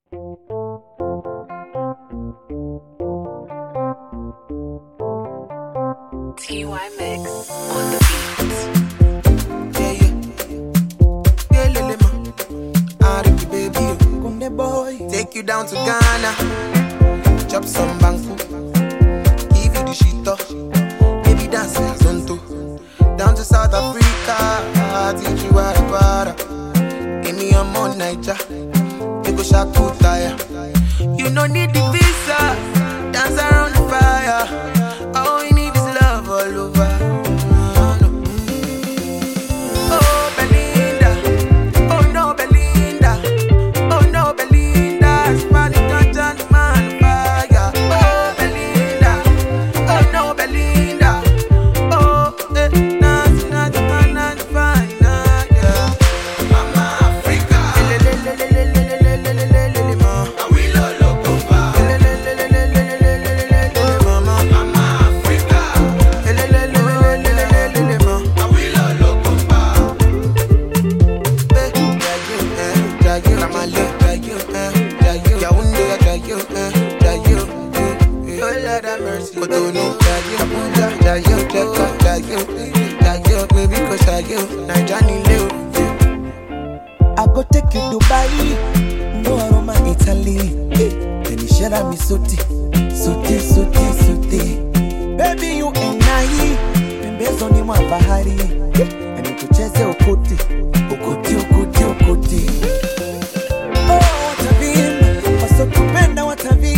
Nigerian versatile singer
street anthem